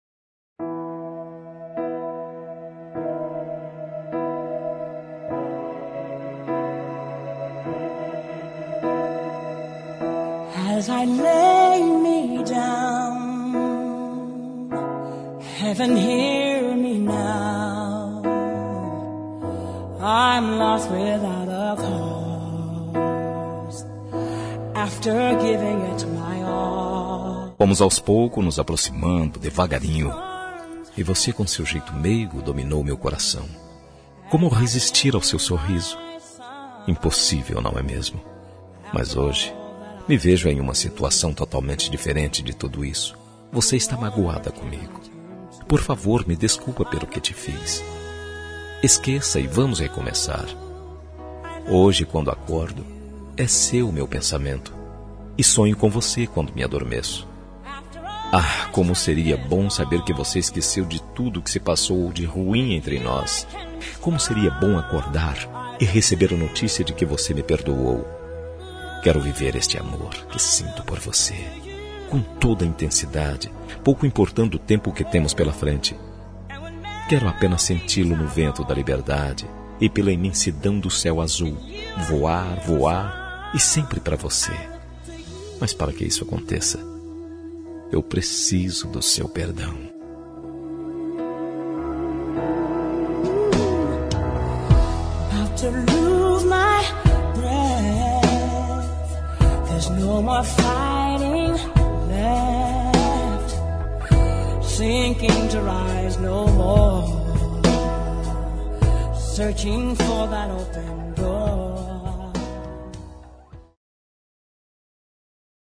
Telemensagem de Desculpas – Voz Masculina – Cód: 402